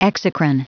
Prononciation du mot exocrine en anglais (fichier audio)
Prononciation du mot : exocrine